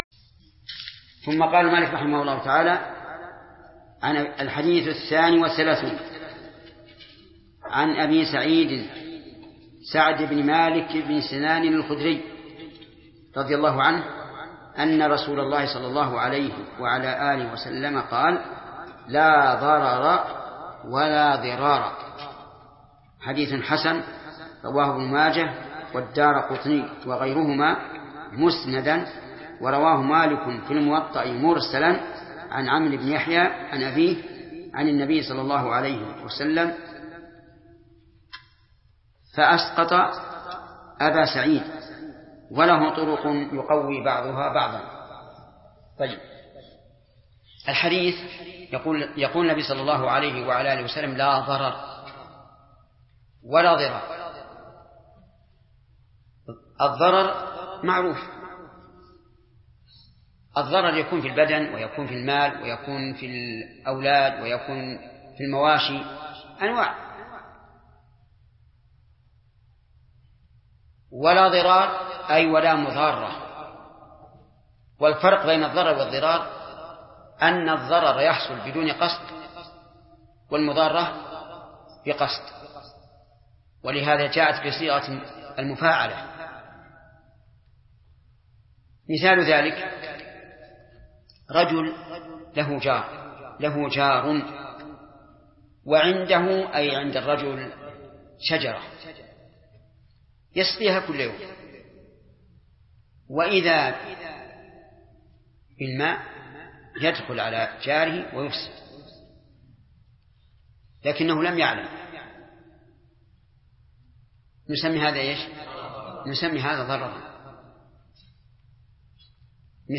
الدرس الرابع والعشرون : من قوله: الحديث الثاني والثلاثون، إلى: نهاية الحديث الرابع والثلاثون.